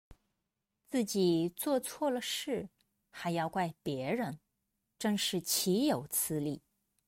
岂(qǐ)有(yǒu)此(cǐ)理(lǐ)